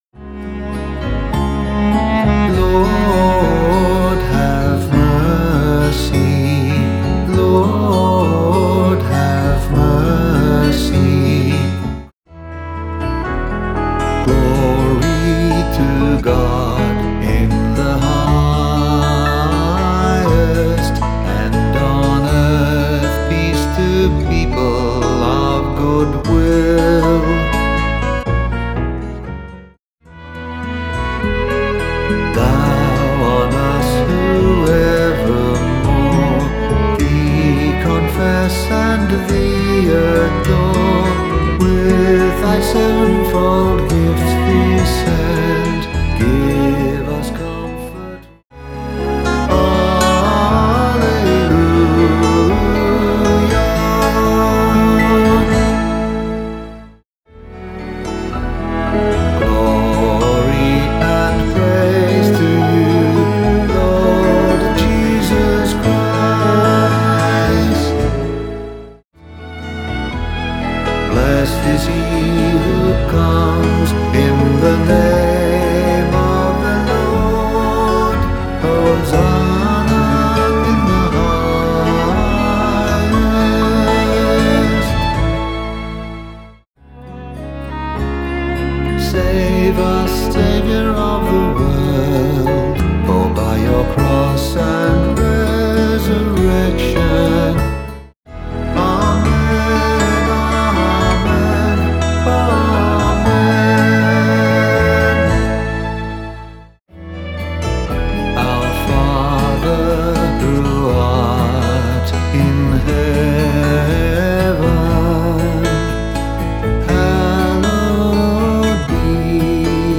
All of the sung parts of Mass